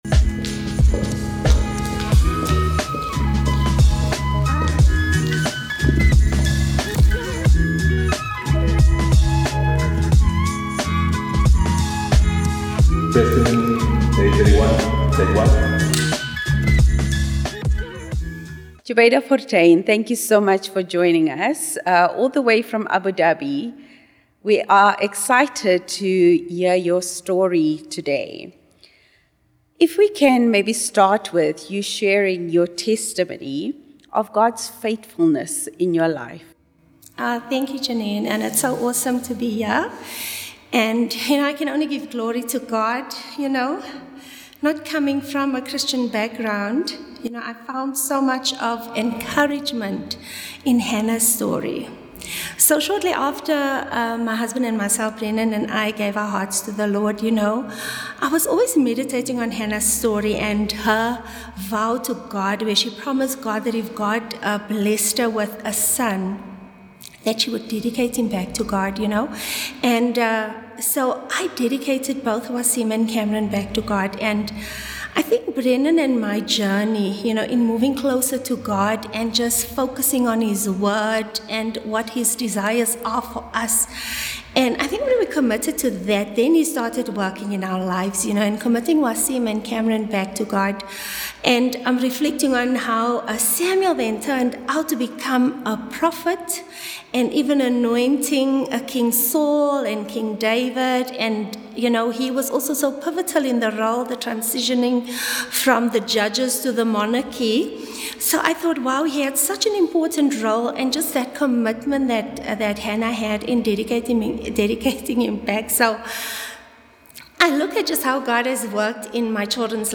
The Brave Testimony: A Christian Journey of Courage Welcome to The Brave Testimony, a Christian platform where women share their powerful stories of faith and courage. Designed to inspire and uplift, The Brave Testimony features heartfelt testimonies from ladies who have faced daily challenges with bravery and grace. Each testimony is a beacon of hope, showcasing how faith in God empowers us to overcome obstacles, whether in our personal lives, careers, or spiritual journeys.